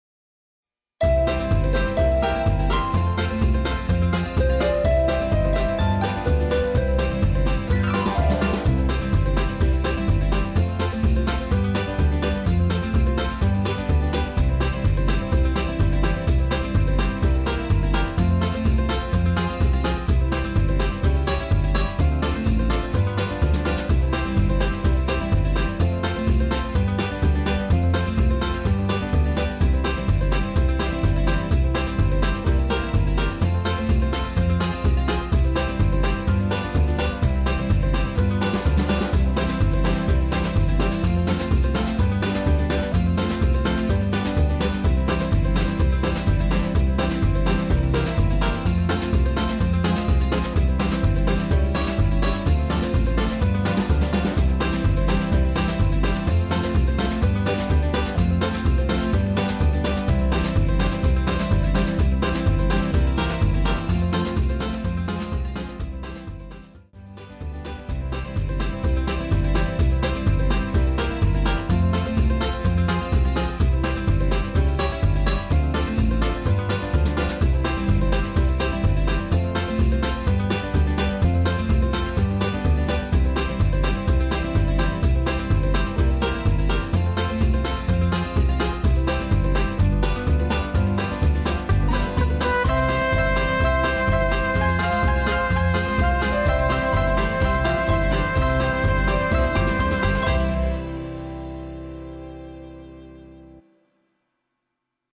Instrumental TRacks
Rhythm & Bells Backing Track